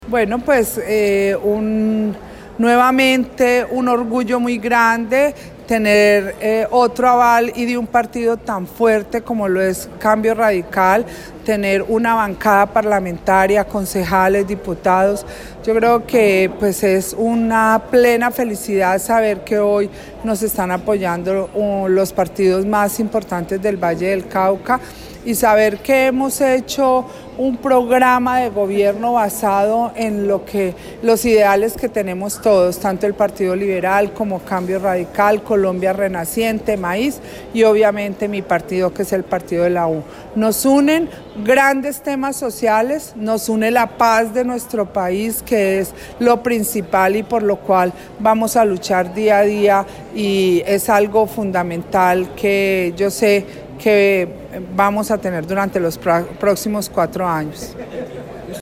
Declaraciones Clara Luz Roldán – Coaval Partido Cambio Radical.